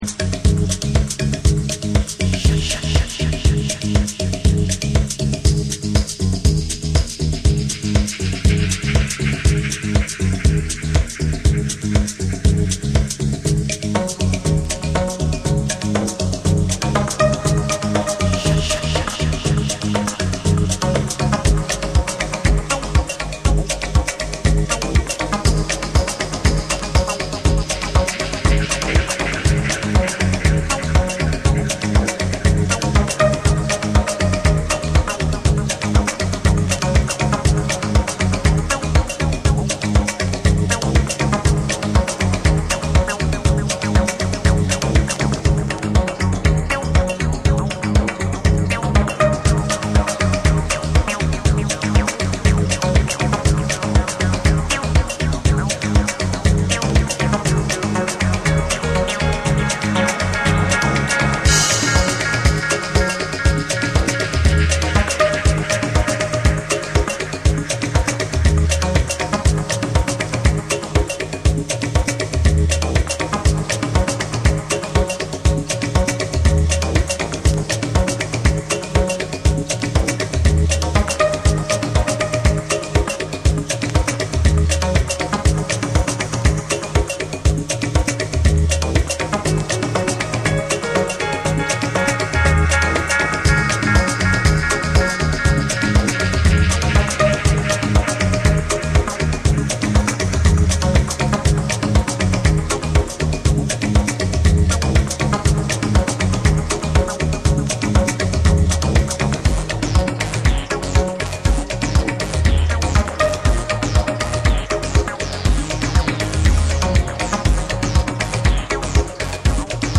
ゆったりとしたグルーヴに柔らかなシンセが重なり、開放感のあるバレアリックな世界観を展開する
TECHNO & HOUSE